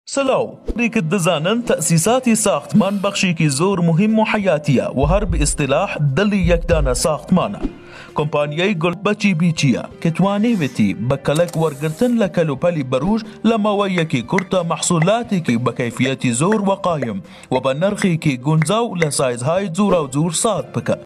Male
Young
Commercial
informative